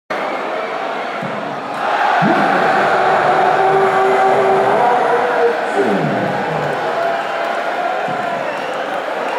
Russ Bray's famous 180 call.